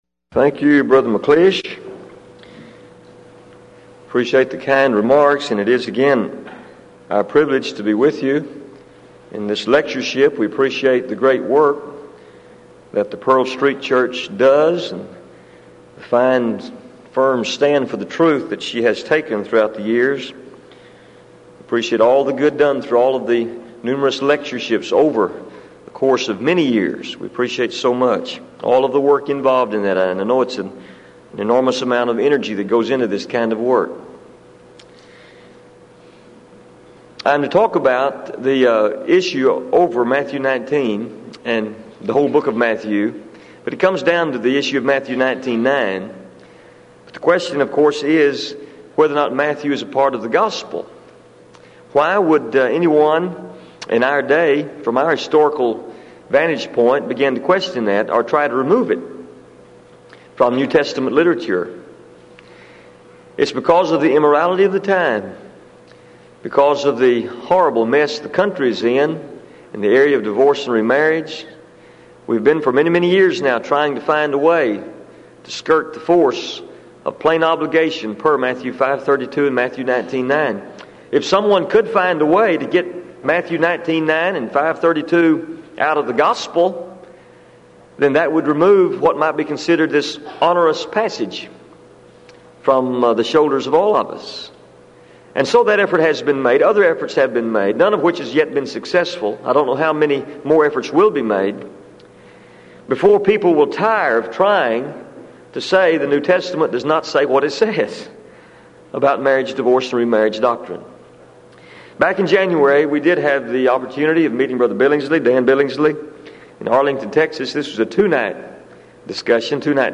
Title: DISCUSSION FORUM: Is the Book of Matthew Part of the New Testament?
Event: 1995 Denton Lectures Theme/Title: Studies In Matthew